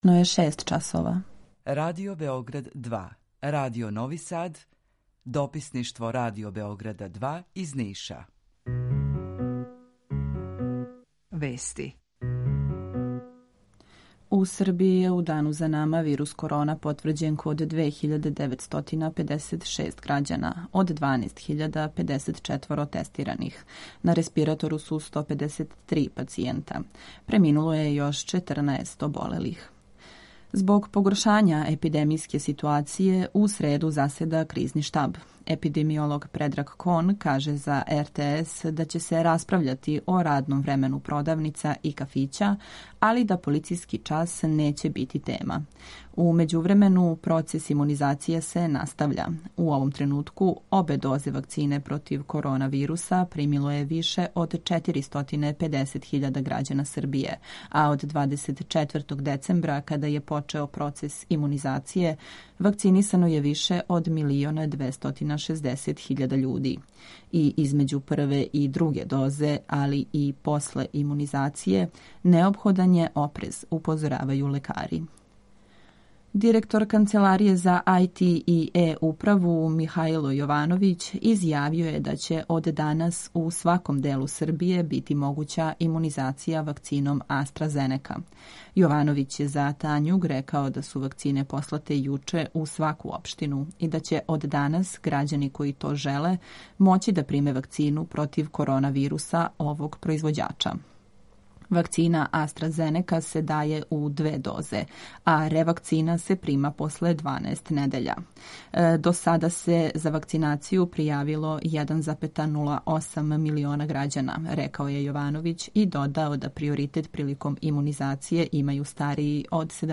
Јутарњи програм заједнички реализују Радио Београд 2, Радио Нови Сад и дописништво Радио Београда из Ниша.
Јутарњи програм из три студија
У два сата, ту је и добра музика, другачија у односу на остале радио-станице.